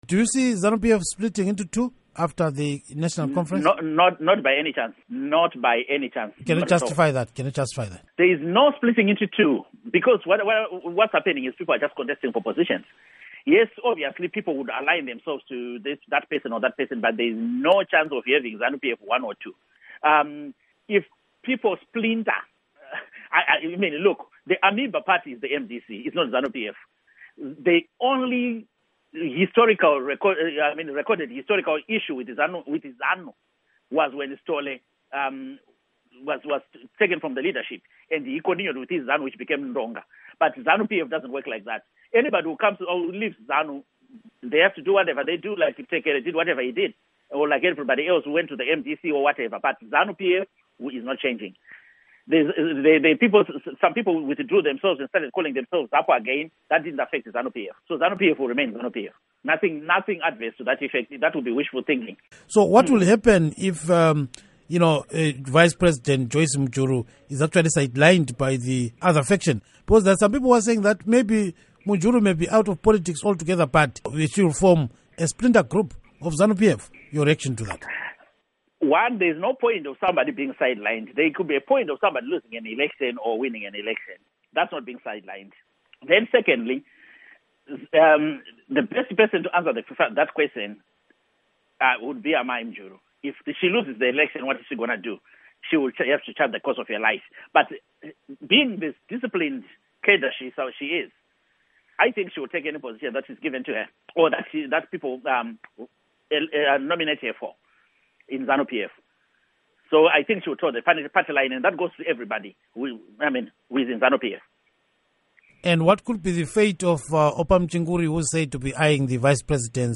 Interview With Nick Mangwana